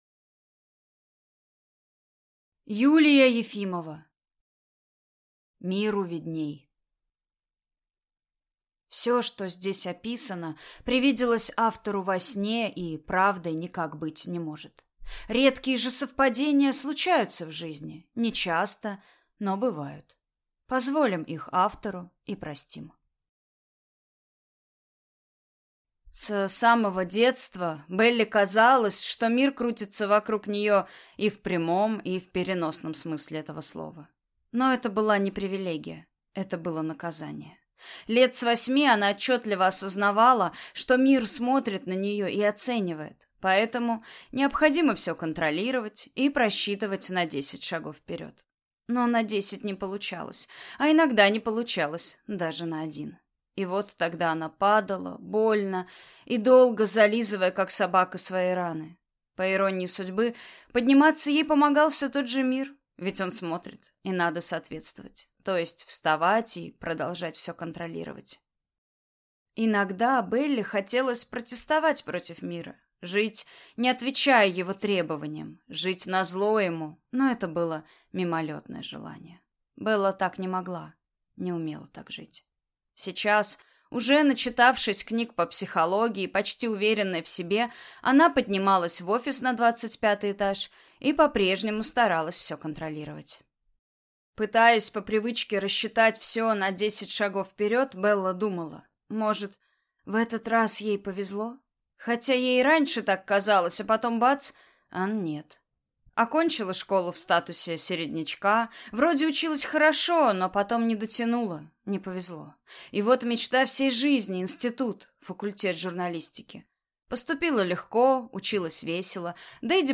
Аудиокнига Миру видней | Библиотека аудиокниг